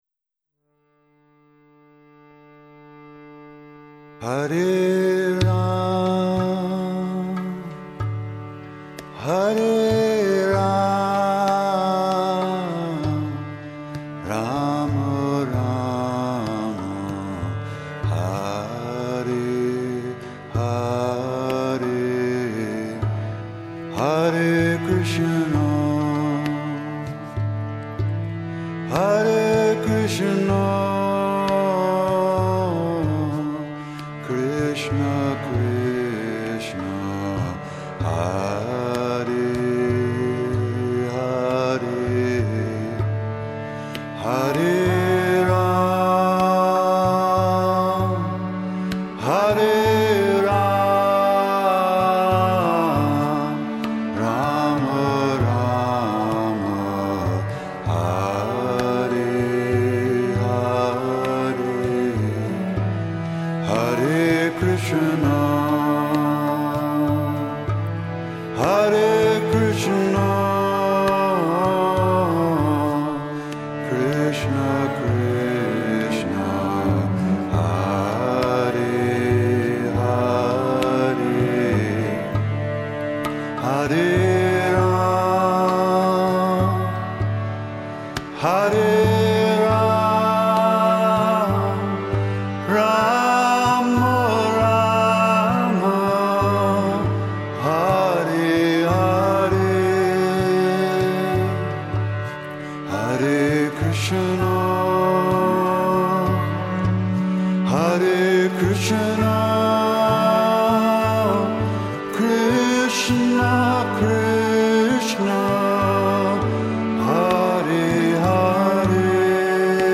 Бхаджаны Вайшнавские бхаджаны